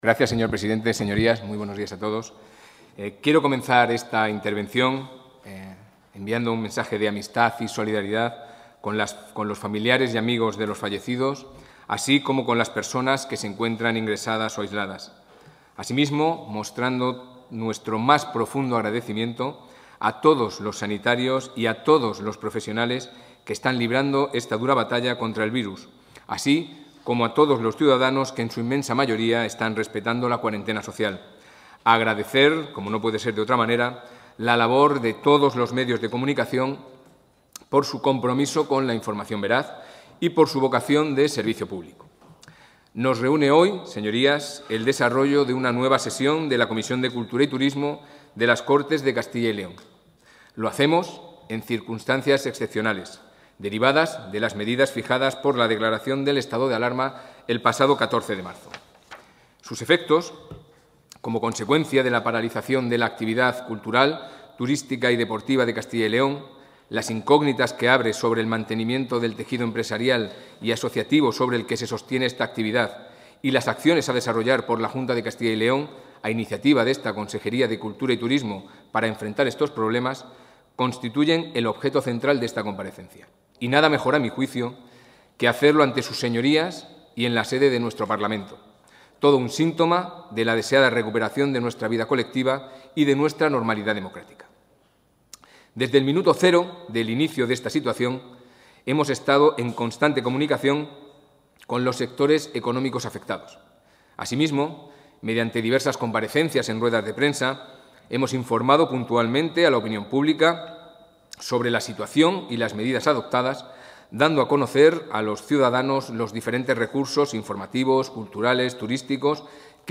El consejero de Cultura y Turismo, Javier Ortega, ha comparecido hoy en las Cortes de Castilla y León para informar de la gestión...
Comparecencia.